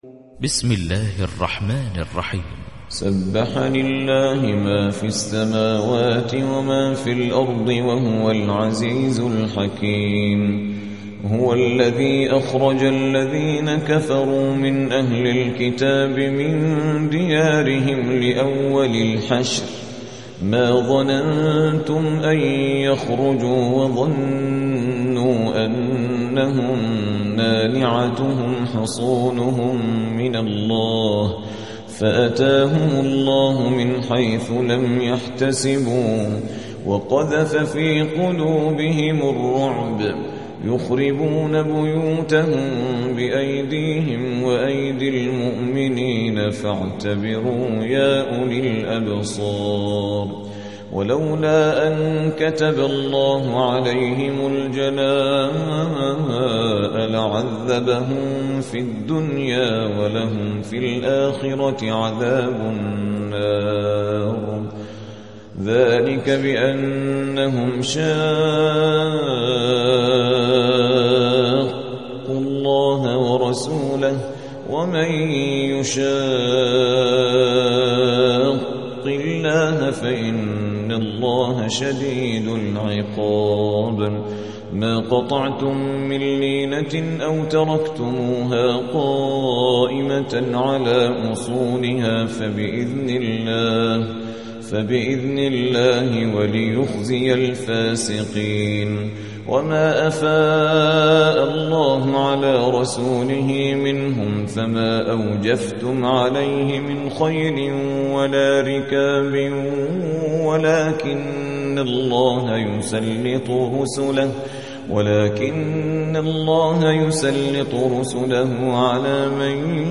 59. سورة الحشر / القارئ